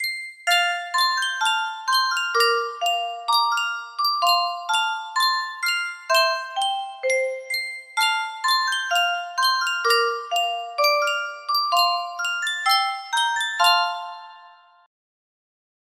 Reuge Music Box - When You and I Were Young, Maggie 6841 music box melody
Full range 60